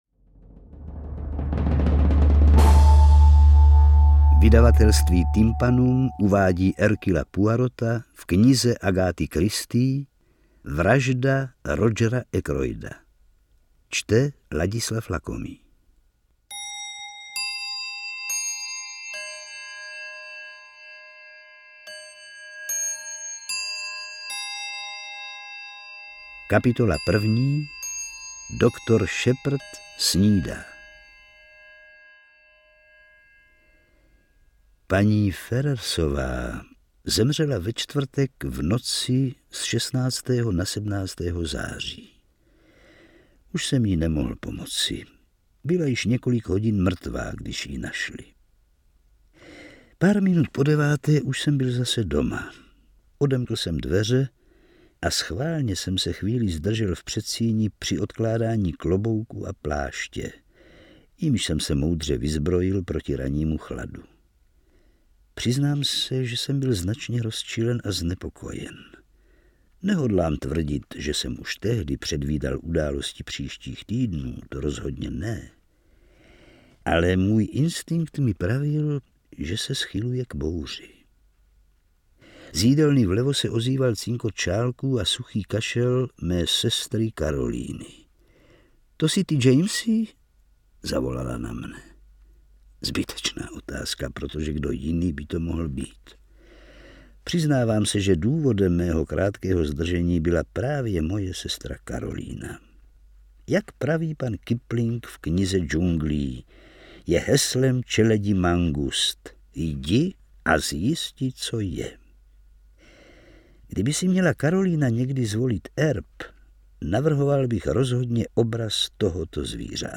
Detektivní příběh ve formátu MP3.